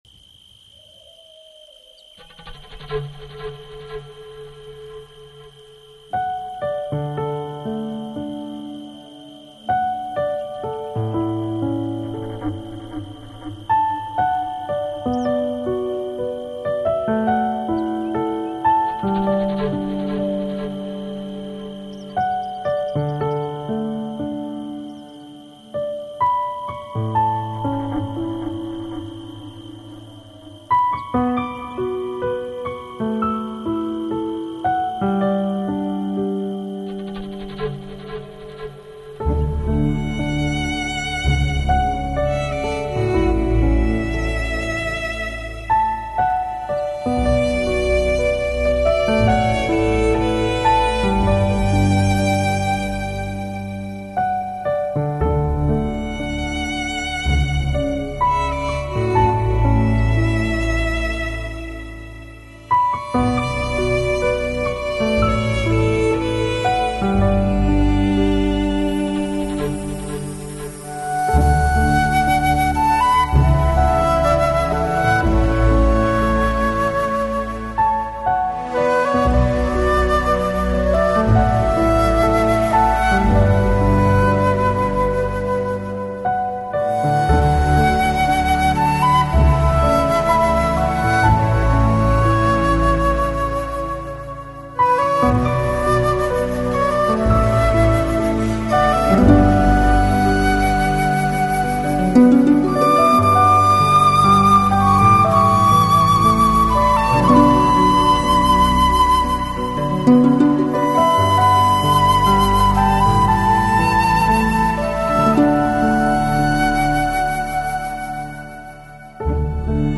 Жанр: Balearic, Lounge, Chillout, Downtempo